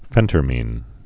(fĕntər-mēn)